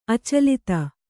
♪ acalita